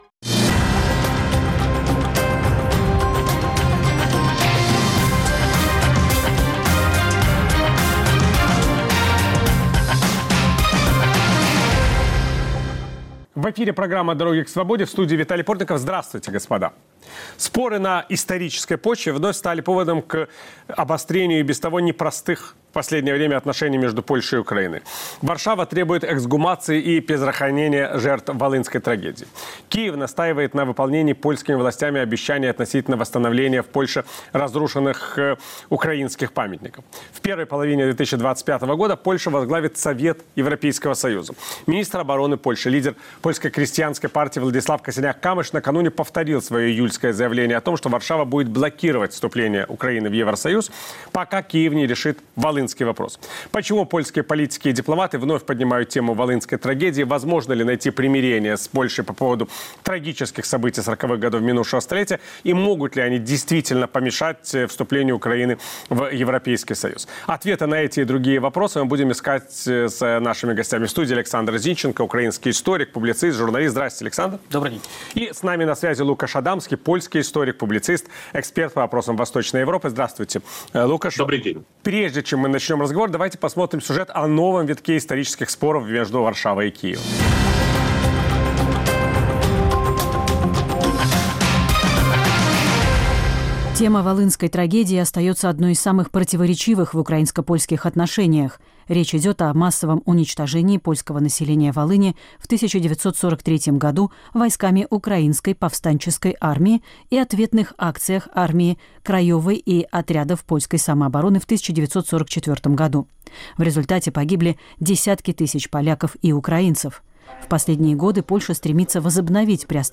украинский и польский историки